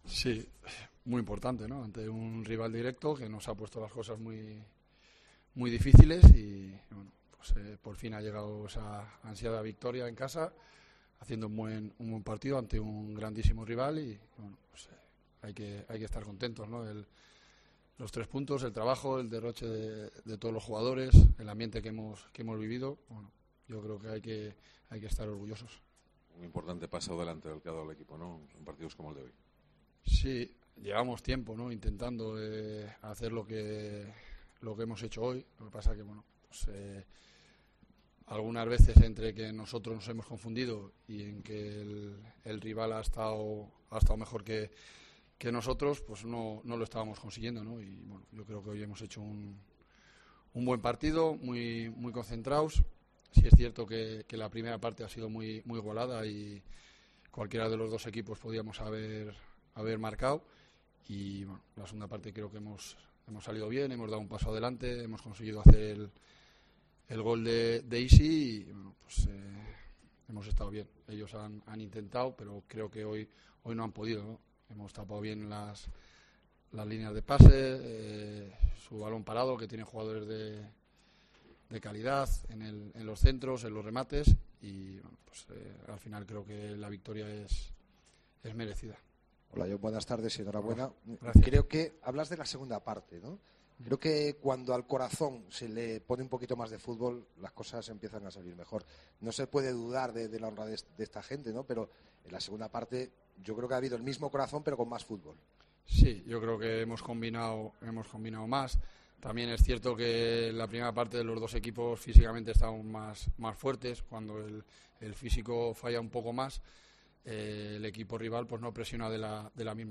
Escucha aquí las palabras del míster de la Deportiva Ponferradina, Jon Pérez Bolo, tras sumar la victoria ante el Pontevedra